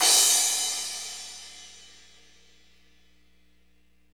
CYM F S C0VR.wav